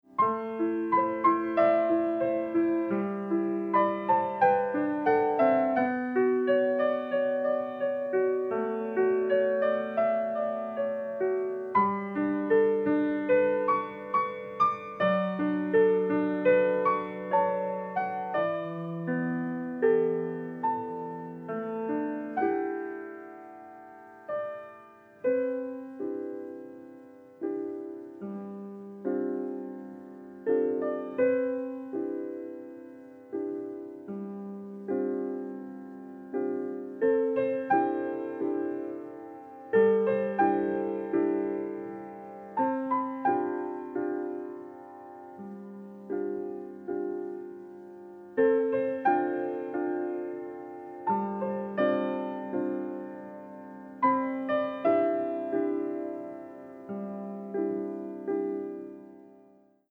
dynamic score